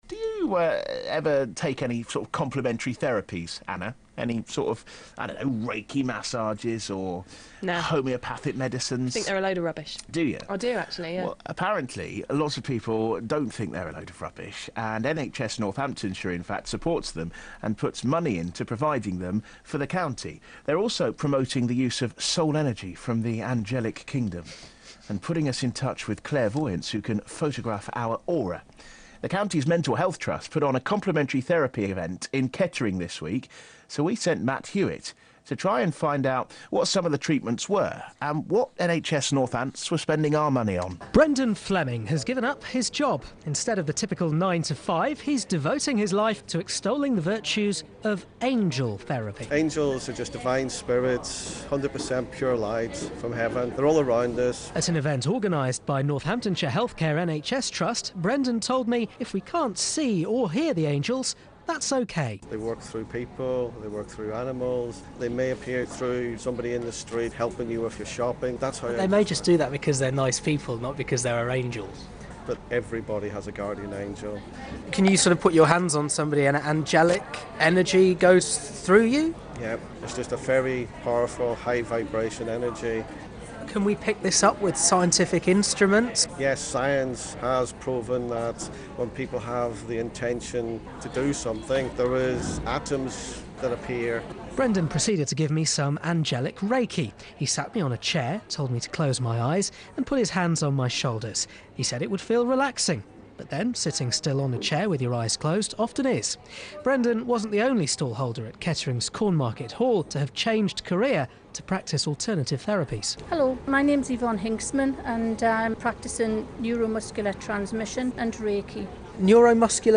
Short interview on BBC Radio Northampton. This followed the News that Northamptonshire NHS Trust had spent taxpayers’ money promoting an alternative medicine fair that promoted angelic reiki, aura photography. "neuromuscular transmission" and horse therapy.